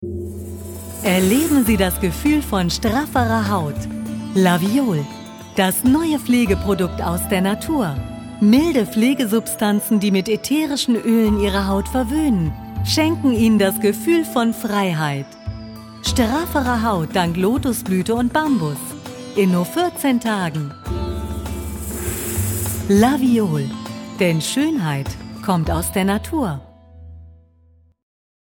Sprechprobe: Werbung (Muttersprache):
german female voice over artist, young voice